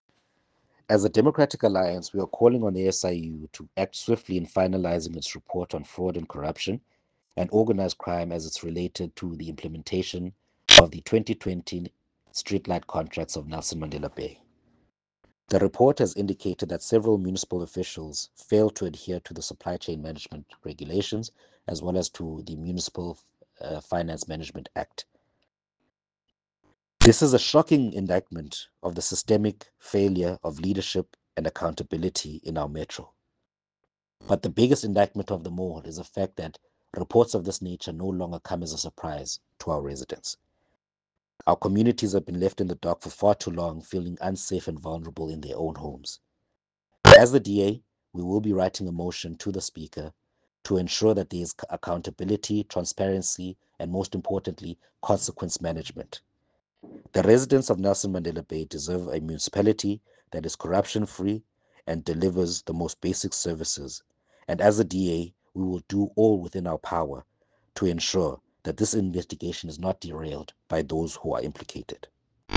Issued by Cllr Ondela Kepe – DA NMB Spokesperson for Electricity and Energy
soundbite by Cllr Ondela Kepe